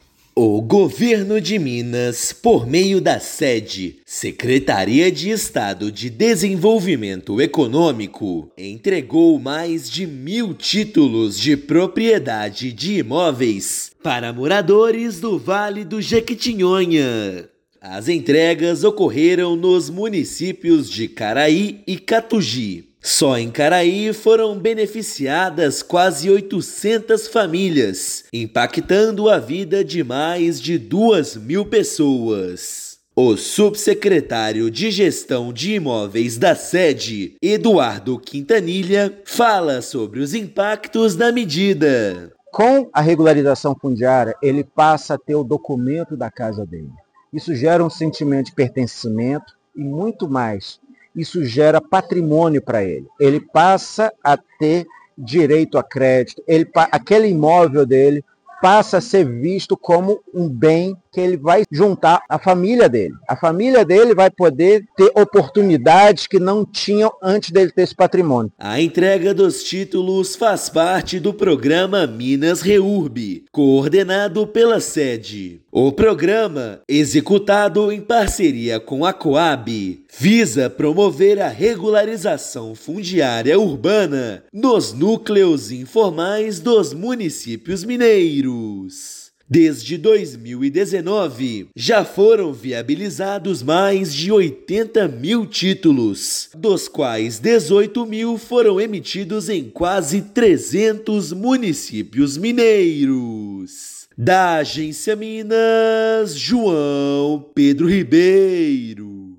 Por meio do Minas Reurb, as entregas impactaram mais de 3 mil moradores em Caraí e Catuji. Ouça matéria de rádio.